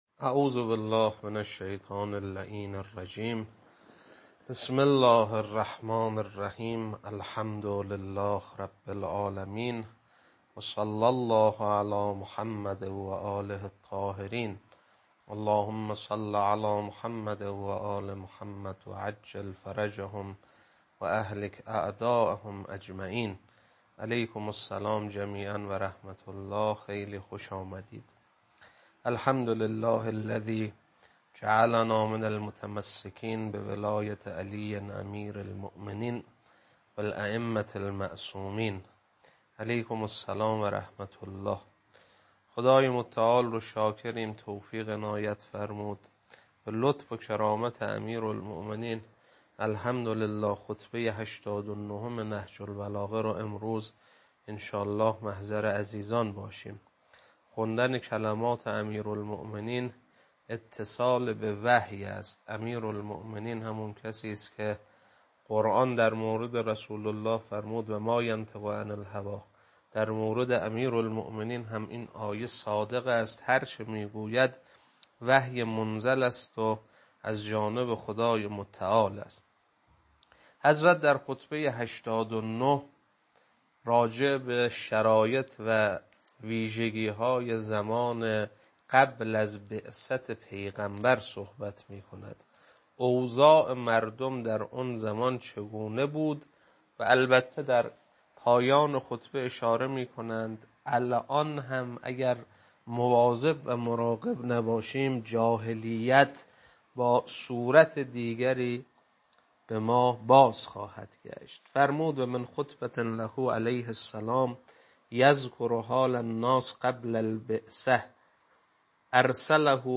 خطبه-89.mp3